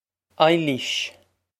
Eye-leesh
This is an approximate phonetic pronunciation of the phrase.